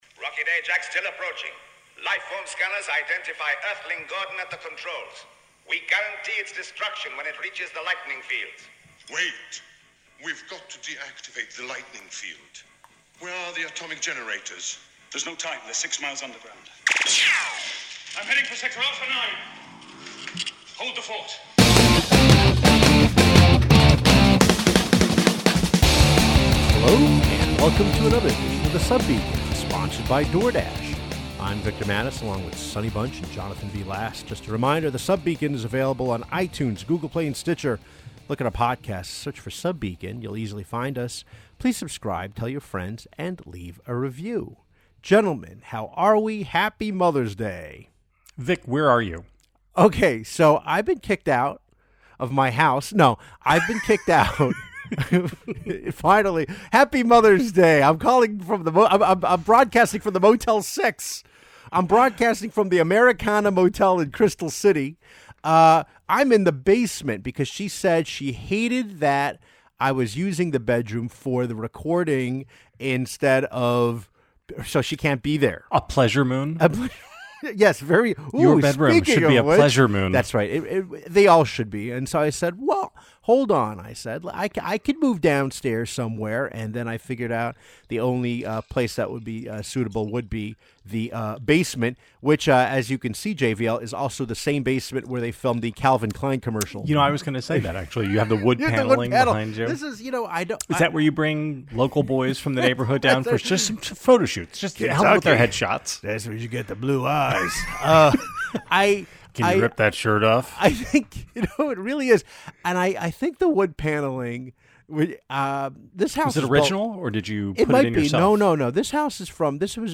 recording in his basement. Plus ZZ Top lyrics explained!